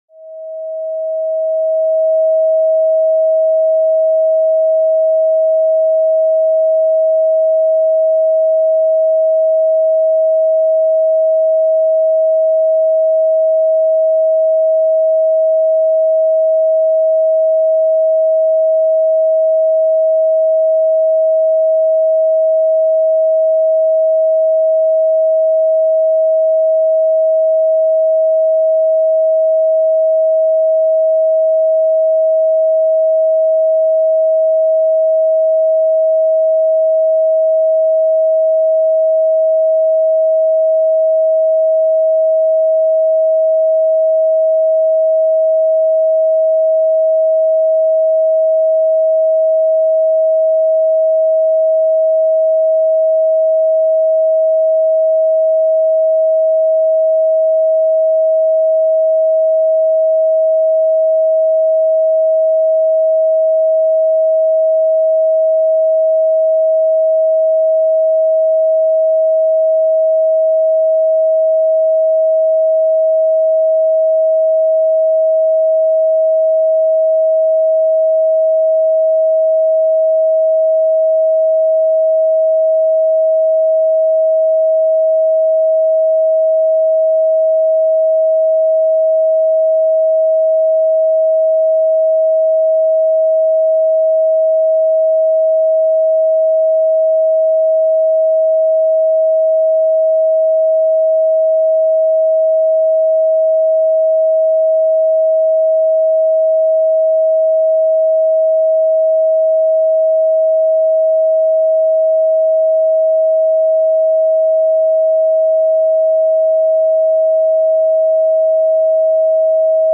La fréquence 639 htz facilite l’équilibre mentale
Cette-frequence-639-htz-influence-lequilibre-et-la-tranquilite.mp3